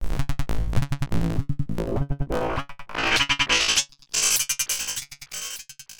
Glitch FX 07.wav